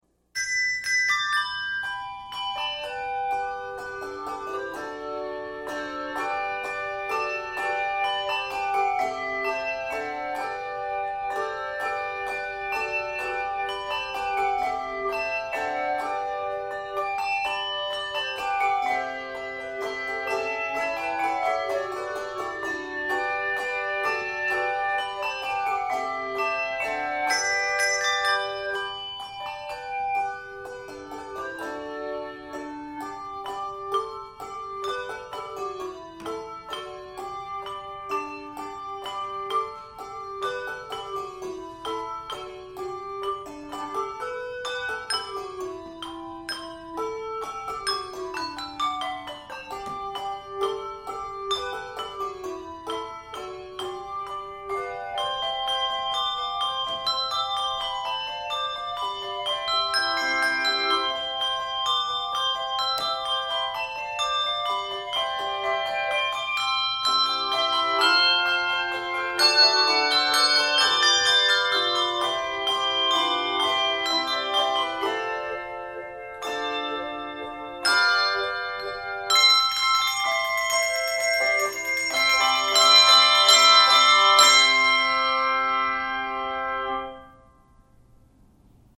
Keys of G Major and C Major.